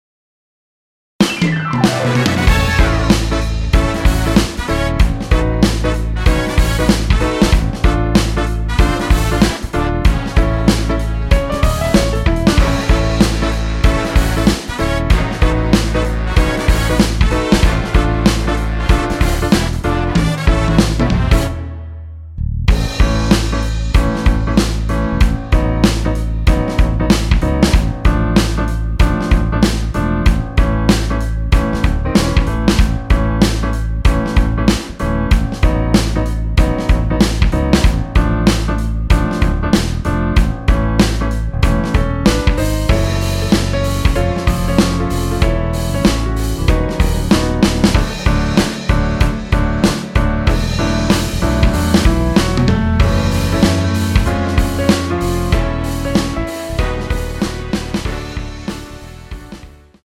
원키에서(-5)내린 MR입니다.
앞부분30초, 뒷부분30초씩 편집해서 올려 드리고 있습니다.
중간에 음이 끈어지고 다시 나오는 이유는
축가 MR